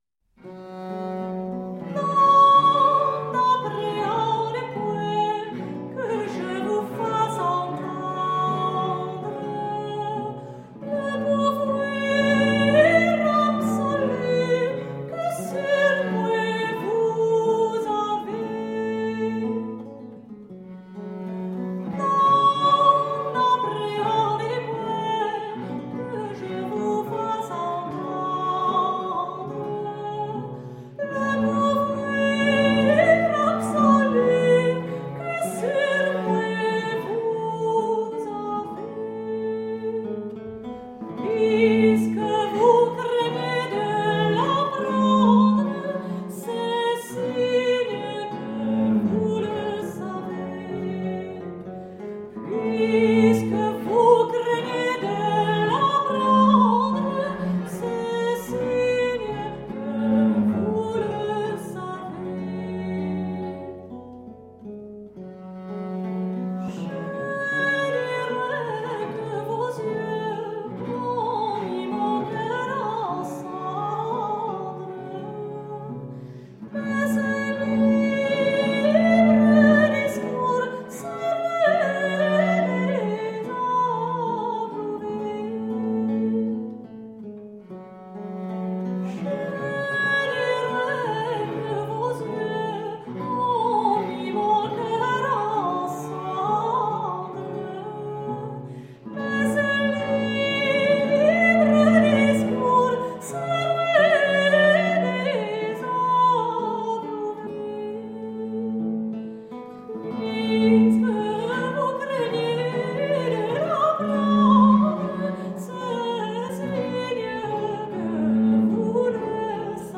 Delicate 17th century chansons.
the three women
with all-original instrumentation
with the intimate feeling of a parlor room recital.